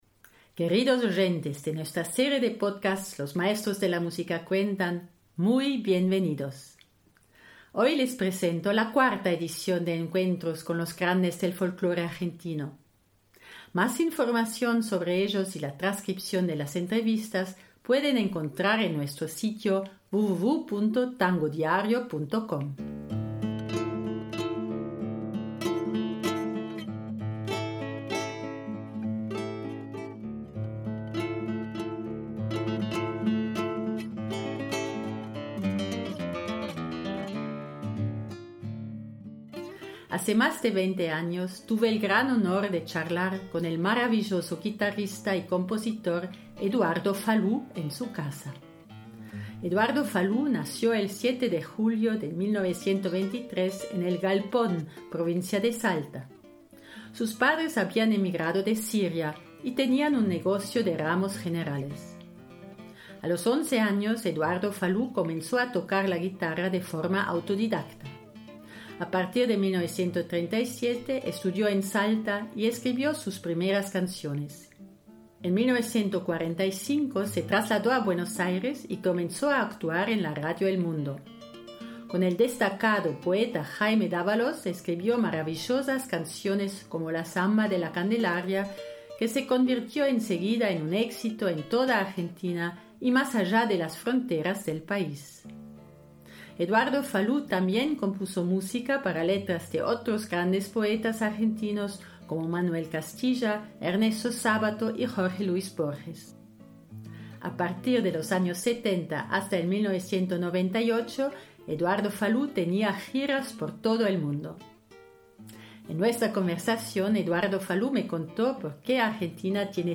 Eduardo Falu - Podcast, entrevista BEST PODCAST
Hace más de 20 años, tuve el gran honor de charlar con el maravilloso guitarrista y compositor Eduardo Falù en su casa.